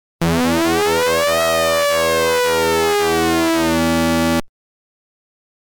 An audio sample where OSC3 syncs OSC1. The frequency of OSC3 is increased by an envelope. OSC3 itself is silent (3Of in FIL menu selected):
mbsidv2_osc_sync.mp3